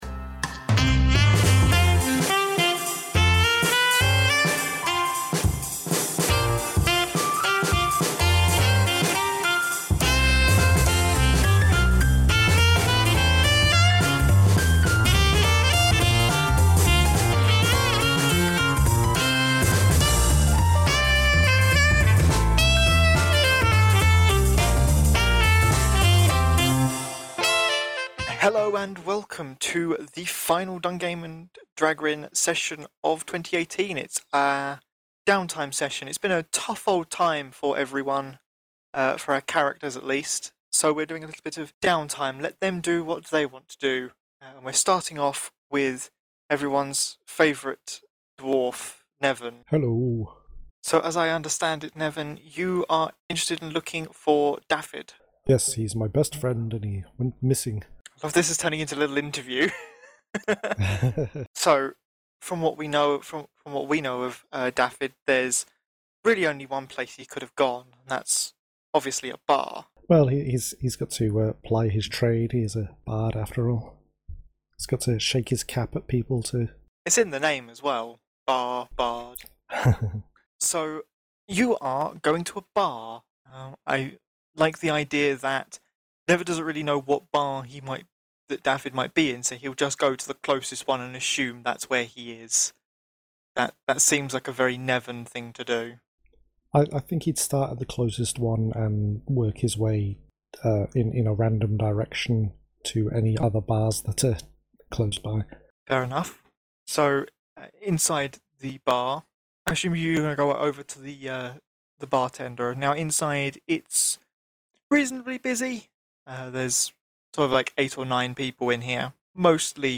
Welcome back to DunGame & DraGrins, our weekly Dungeons & Dragons podcast.